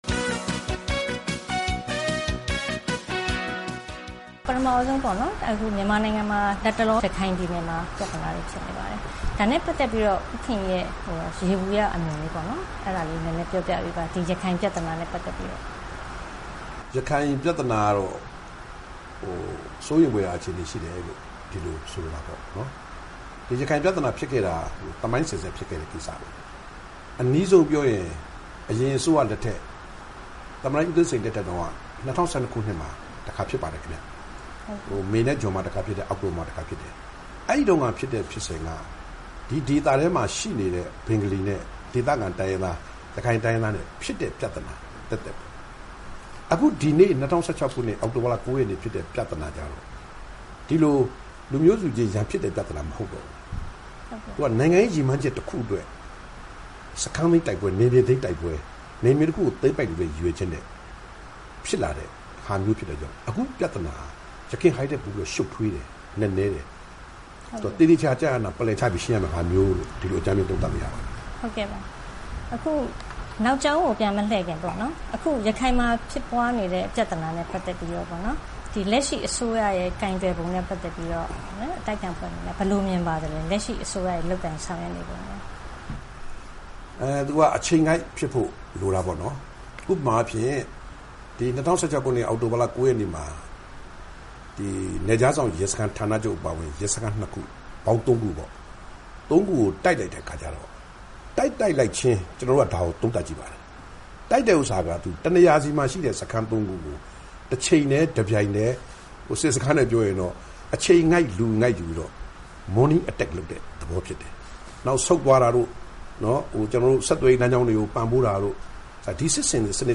ဦးခင်ရီ ကို တွေ့ဆုံမေးမြန်ထားပါတယ်။